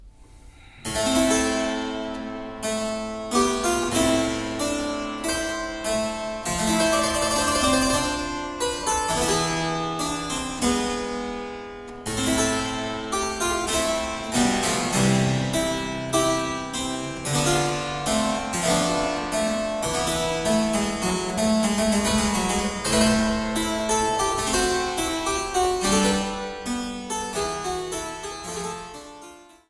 Virginal und Cembalo